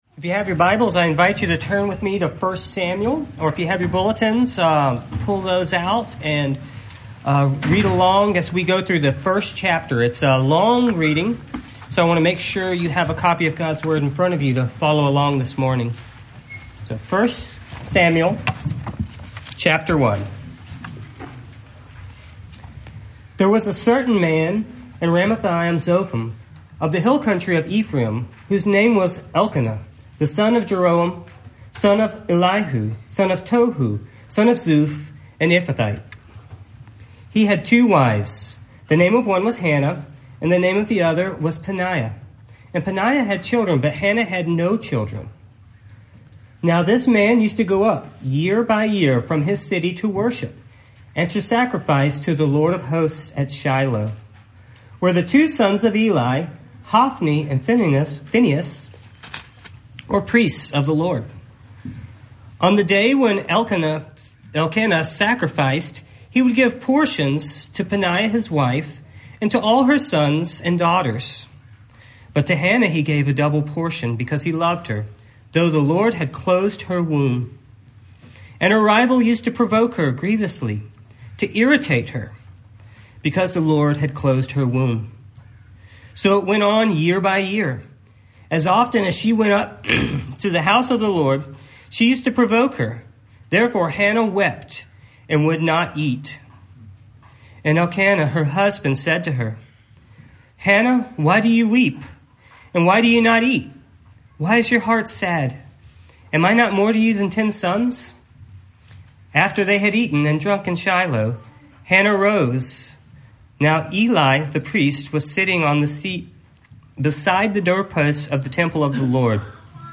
1 Samuel 1:1-28 Service Type: Morning Main Idea